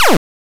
EcoProjectile.wav